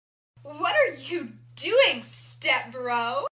What are you doing Step Bro Sound Effect.mp3